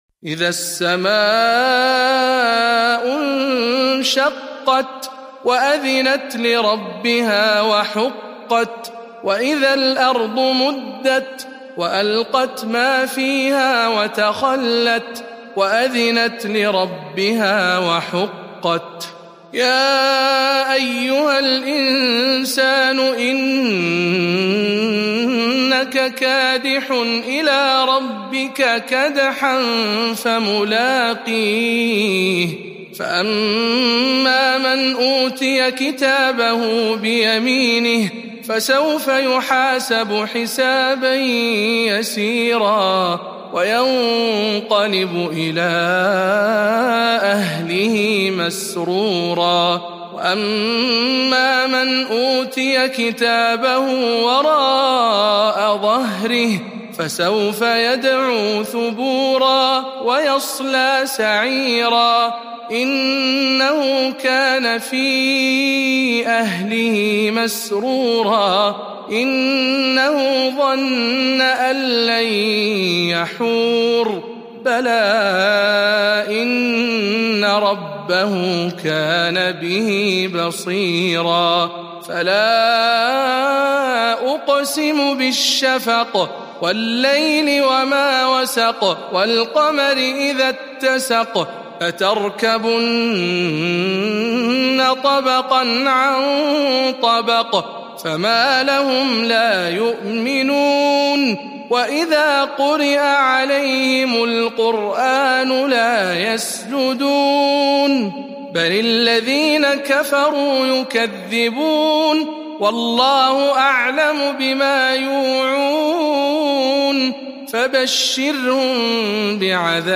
083. سورة الانشقاق برواية شعبة عن عاصم - رمضان 1441 هـ